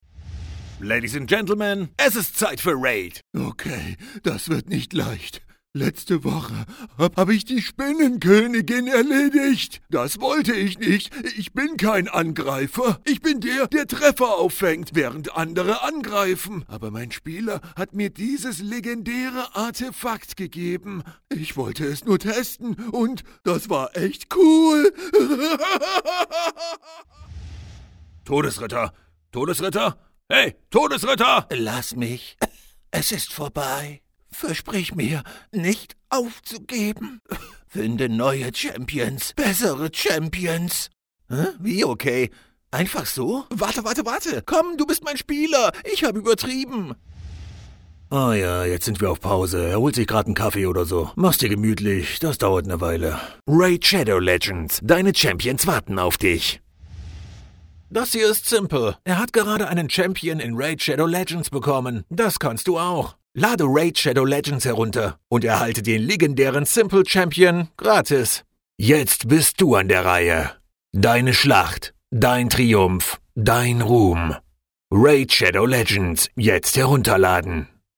Accomplished German voiceover artist with over 20 years experience.
A modern, warm and dynamic voice equally at home in his native German or english
Games, Fantasy, Dynamic, Dark